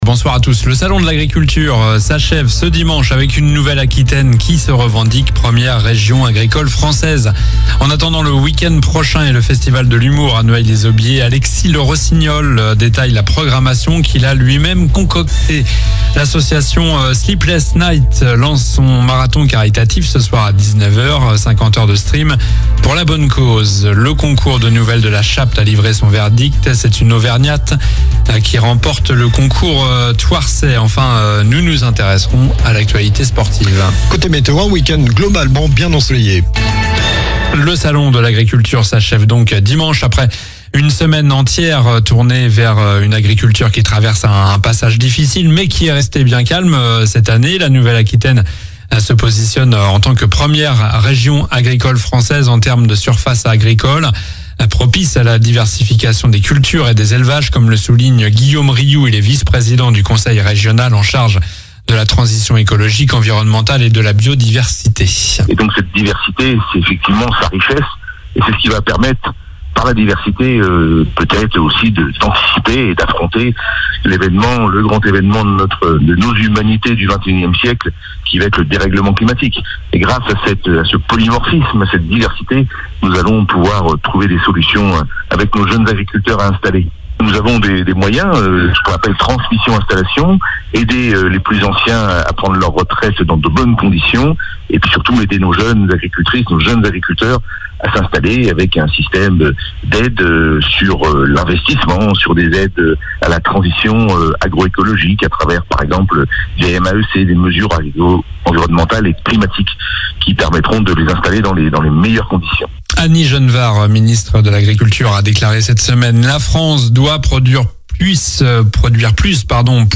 Journal du vendredi 28 février (soir)